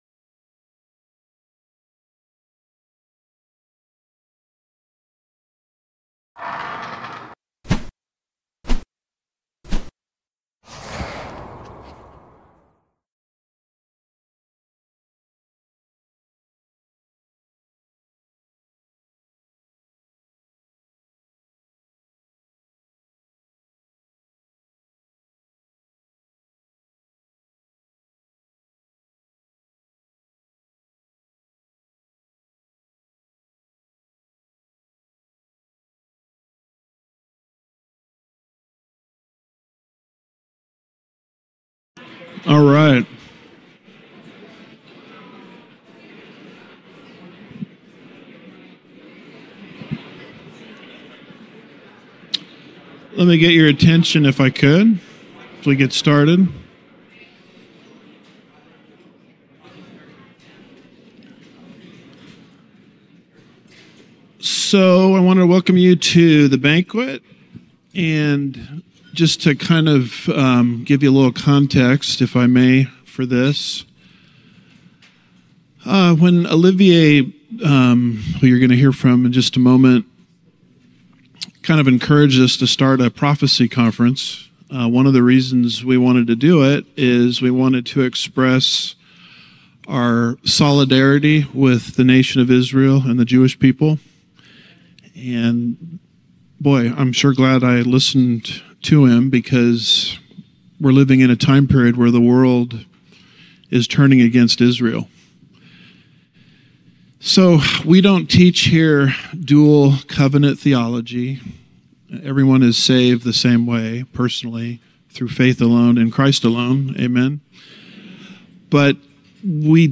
Home / Sermons / Israel: What’s the Big Deal?
2026 • 2026 Prophecy Conference Listen Now Download Audio Previous Sermon Technocracy Rising